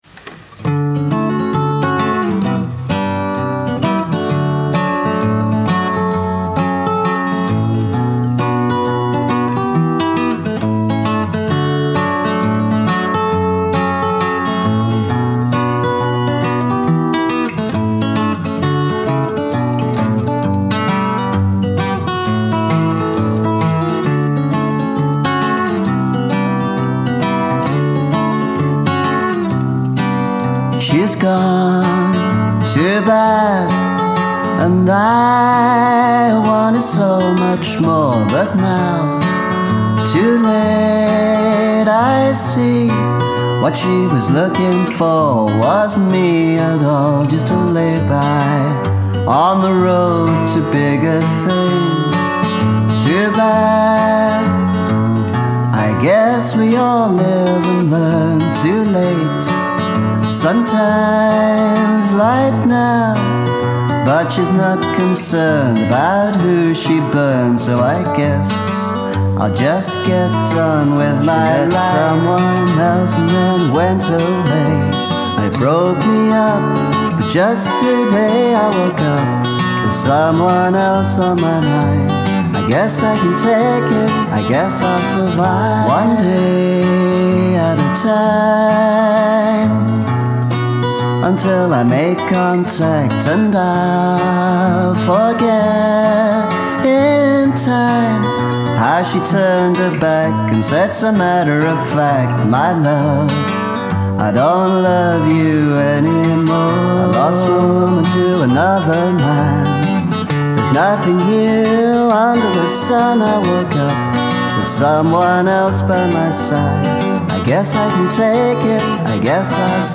My Les Paul copy sounds more than usually out-of-tune with the acoustic guitars.
Vocal, acoustic lead guitar, electric lead guitar.
acoustic guitar